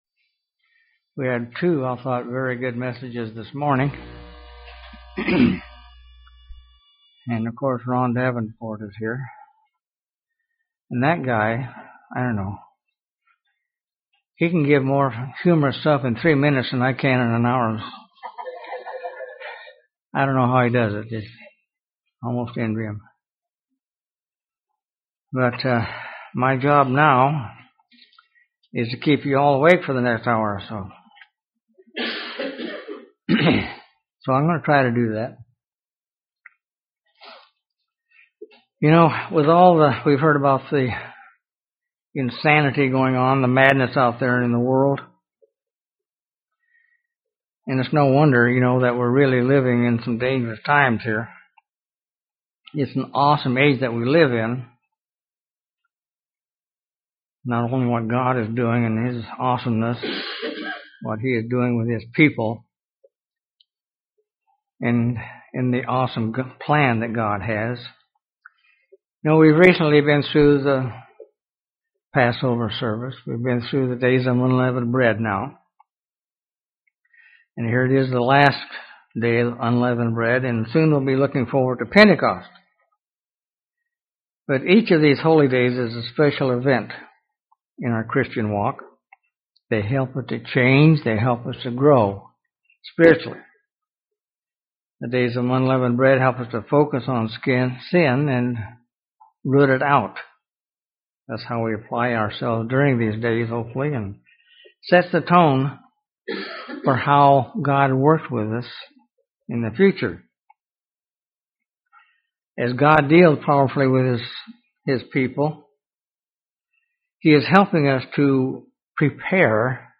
Given in Huntsville, AL